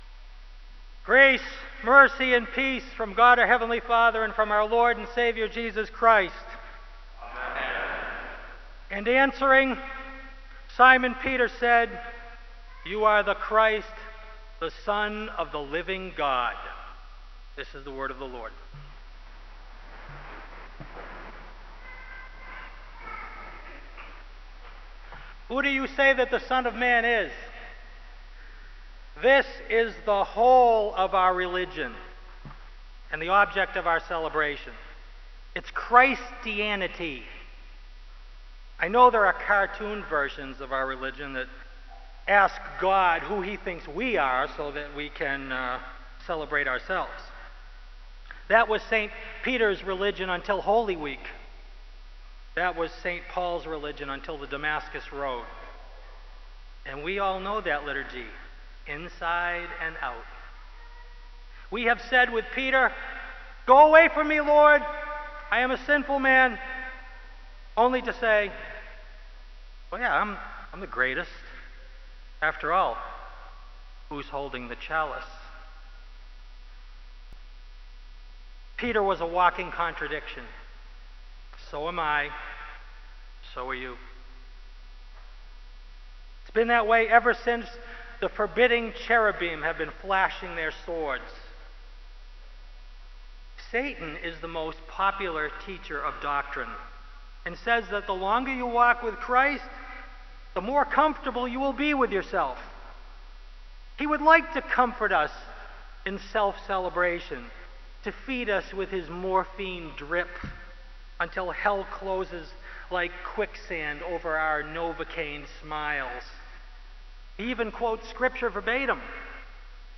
Kramer Chapel Sermon - June 29, 2004